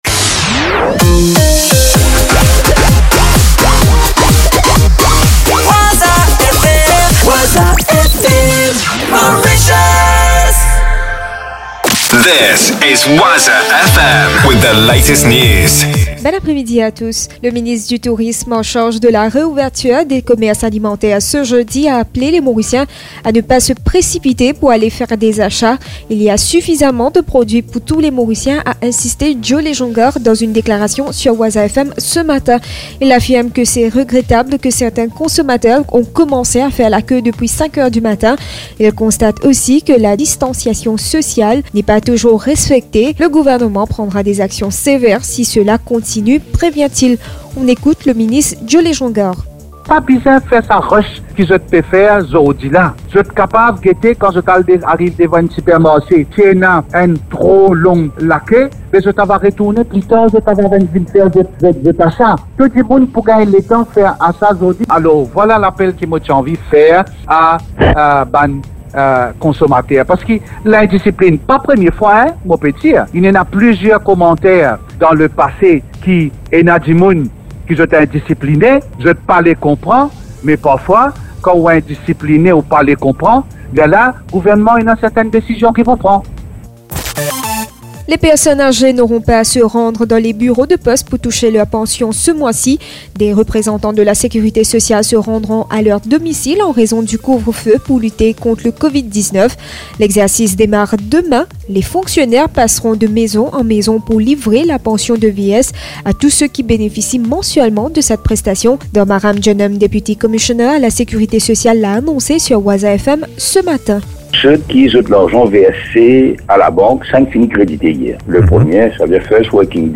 News 02.04.2020 17.00